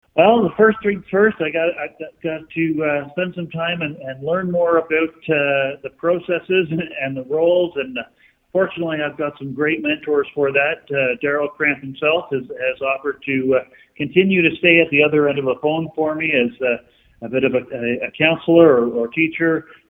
We asked Bresee what he is now looking forward to as MPP.